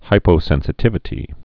(hīpō-sĕnsĭ-tĭvĭ-tē)